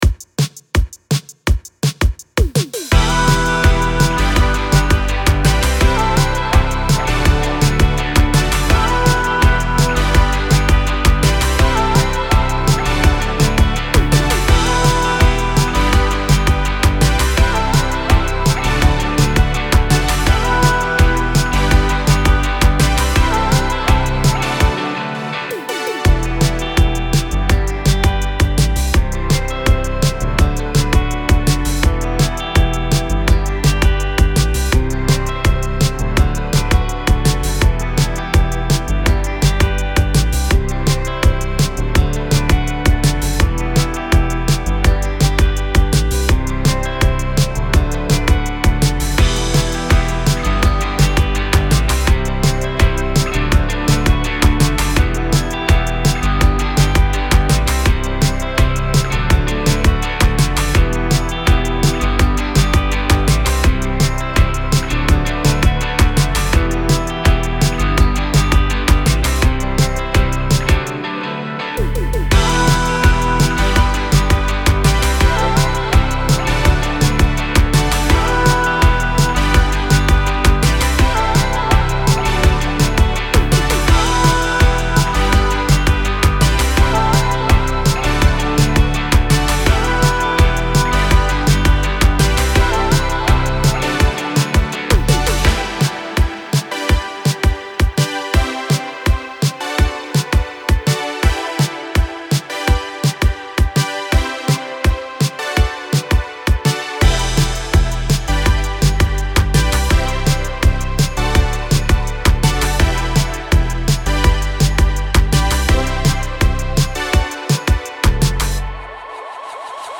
Hier mal ein Instrumental von mir. Alles mit Logic Sounds und Effekten.